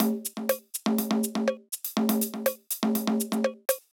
ORG Beat - Perc Mix 1.wav